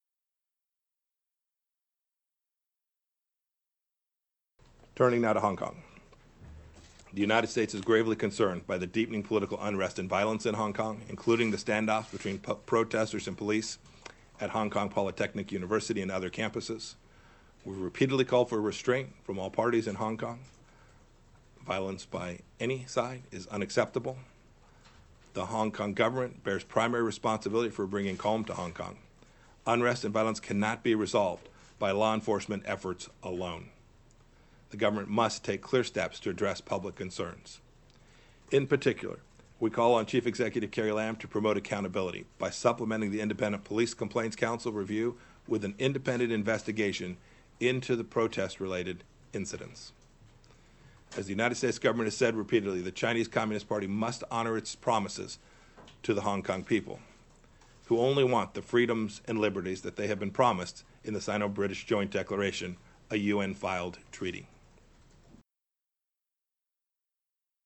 美國國務卿蓬佩奧在美東時間星期一下午(中國與香港時間週二凌晨)在國務院會見記者時提及到香港在剛過去的週末發生的示威與警察進攻香港理工大雪校園的事件時表示﹐呼籲警方與示威者各自克制﹐並認為香港政府在恢復香港平靜方能負有主要責任。